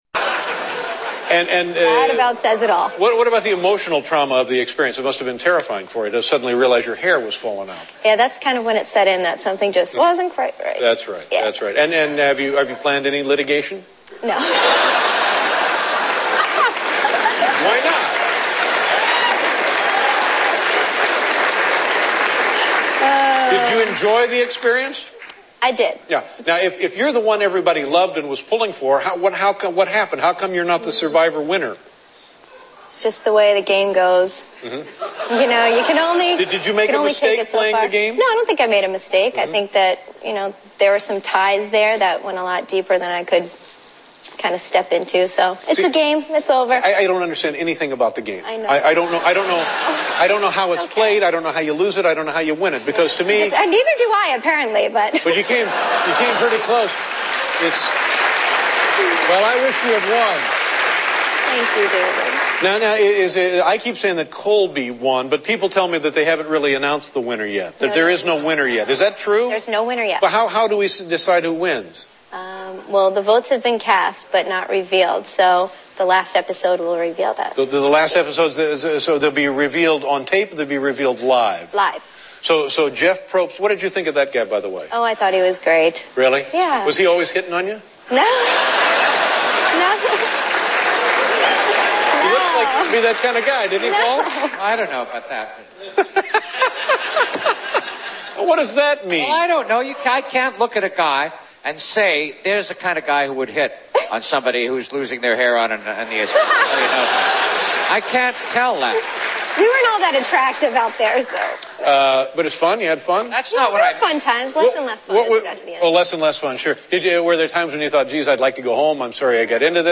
David Letterman Interview pt2
Tags: The View Barbara Walters Rosie O'Donnell Whoopi Goldberg Talk Show